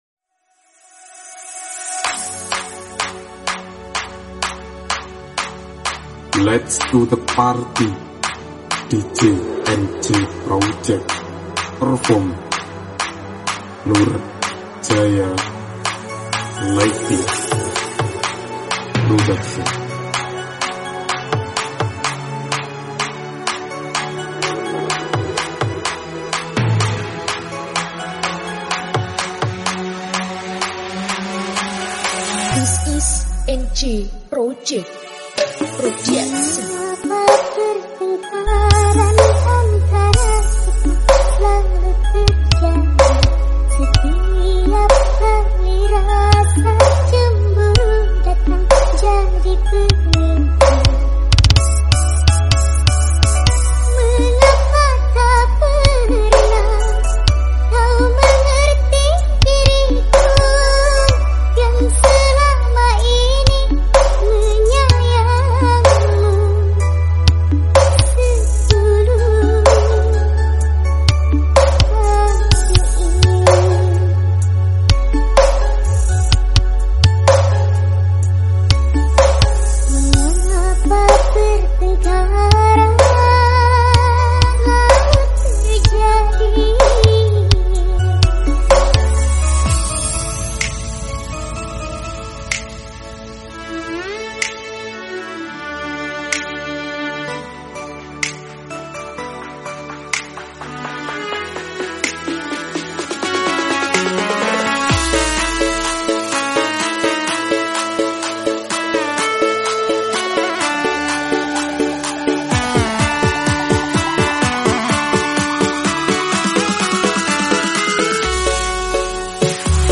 battle bass